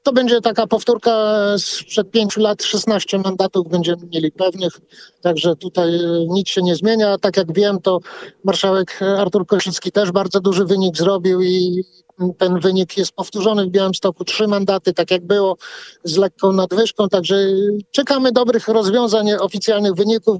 Z informacji, które do nas spływają wynika, że mamy większość w sejmiku – mówił na naszej antenie wicemarszałek województwa podlaskiego Marek Olbryś.